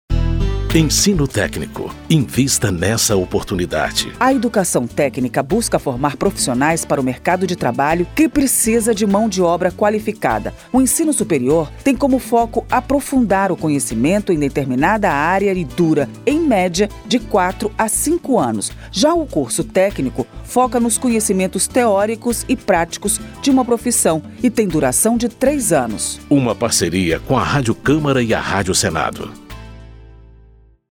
Por isso, a Rádio Câmara e a Rádio Senado criaram cinco spots com informações sobre educação técnica e profissional, mostrando o valor das carreiras técnicas e incentivando o investimento nesse setor.